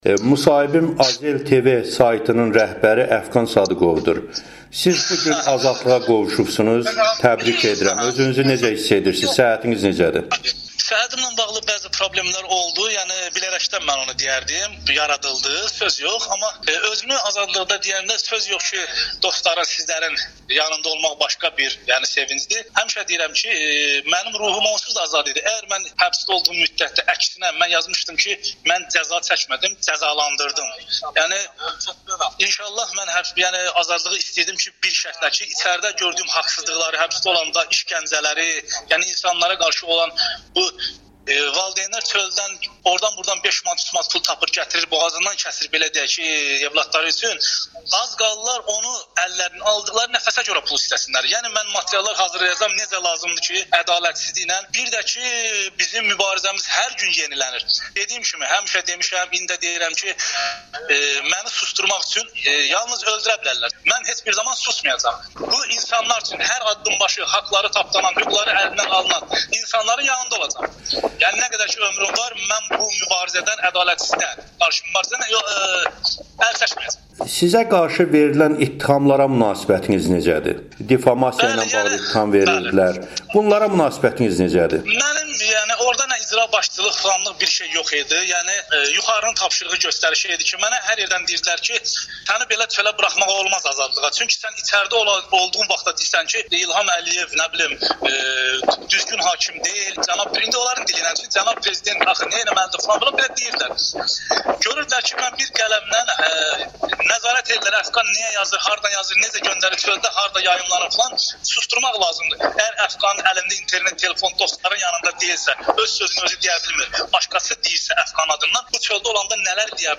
[Jurnalistlə müsahibə]